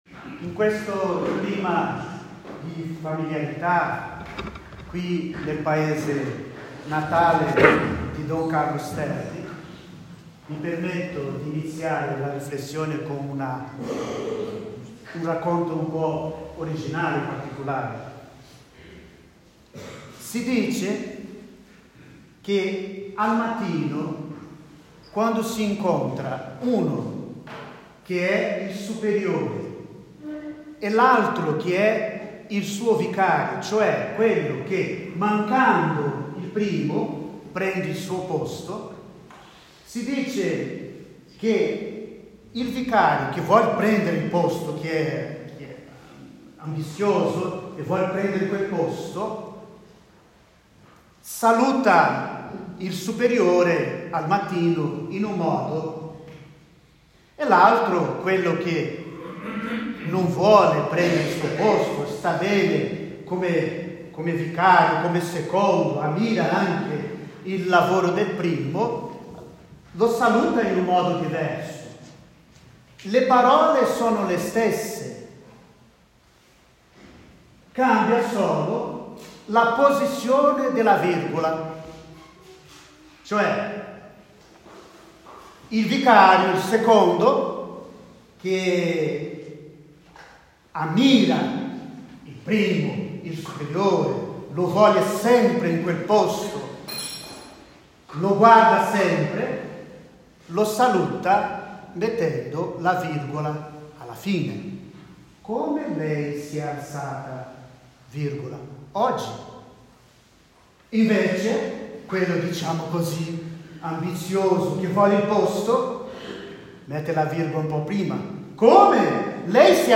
13 ottobre 2024: Celebrato il 150° della nascita di Don Sterpi nel suo paese natale di Gavazzana – FOTO – AUDIO omelia – VIDEO - Basilica Santuario Madonna della Guardia